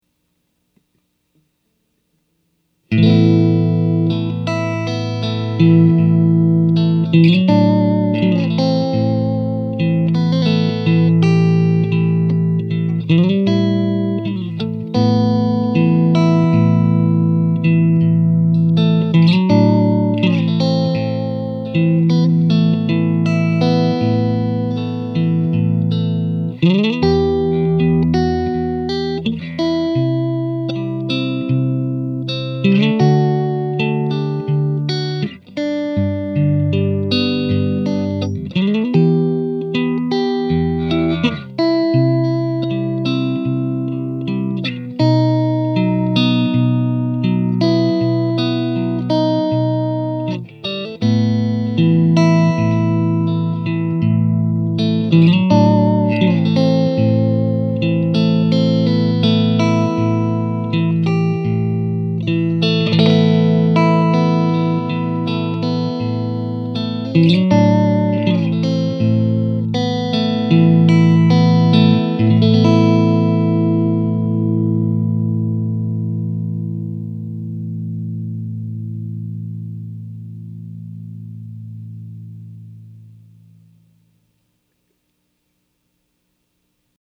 I’m playing my Strat plugged straight into the clean channel of the VRX22. In some sections you could swear that the amp has a reverb, but that’s the solid-state rectifier simulating the sag of a tube rectifier. Also, this is the raw recording of the amp: No EQ, no filtering. The master volume was flat out, with the gain control around midway. My mic was about about 10″ away pointed directly at the center of the speaker cone.
🙂 A Strat on the other hand doesn’t have that much sustain, so it brings out the sustaining quality of the amp much better.
vrx22-clean-power-strat.mp3